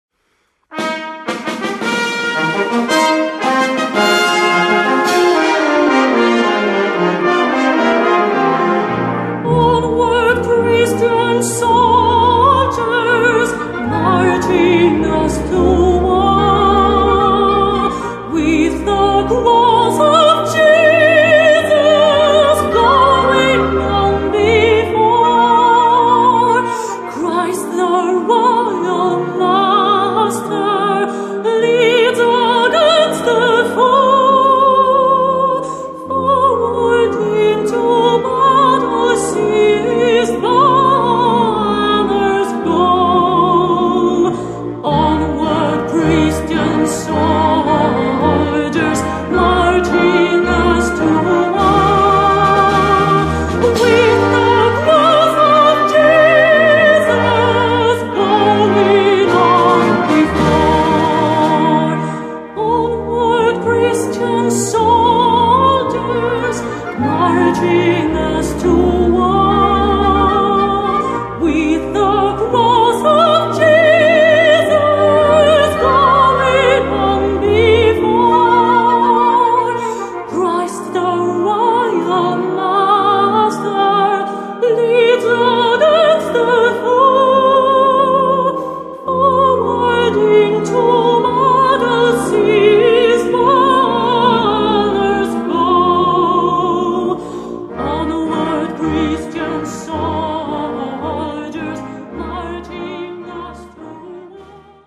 Voicing: Brass Band